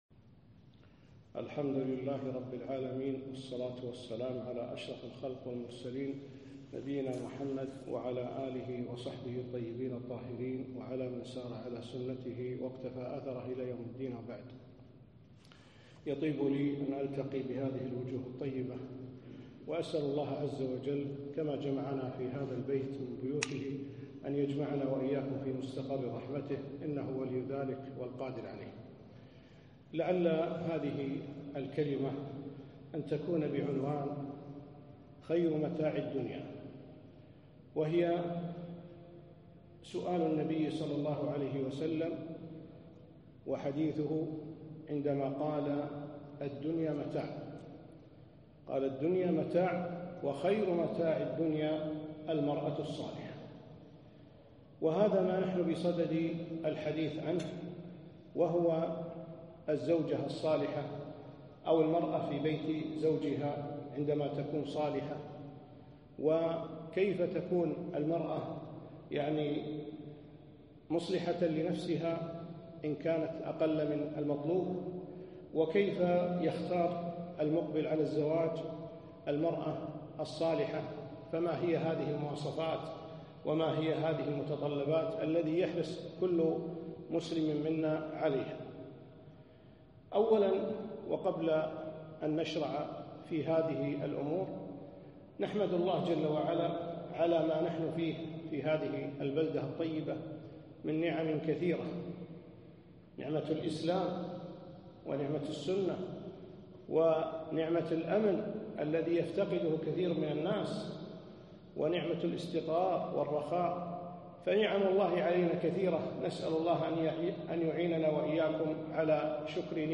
محاضرة - خير متاع الدنيا